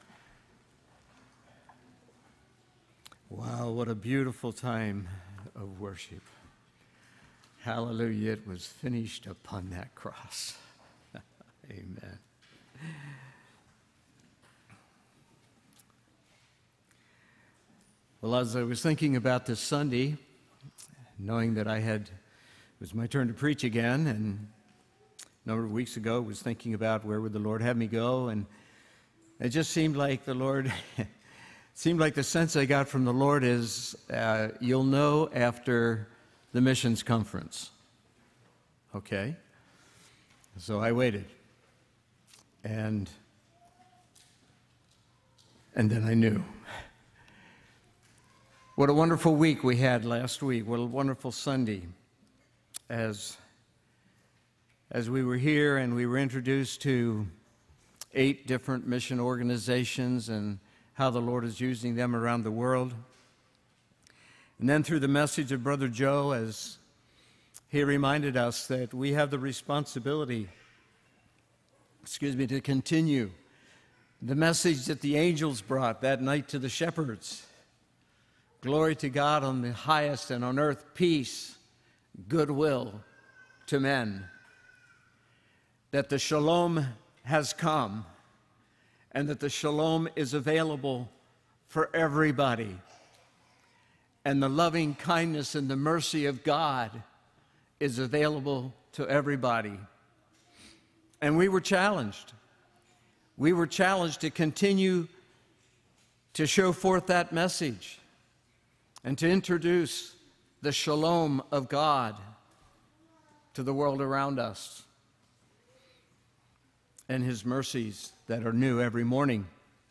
A message from the series "Sunday Morning - 10:30."